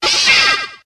Grito de Perrserker.ogg
Grito_de_Perrserker.ogg.mp3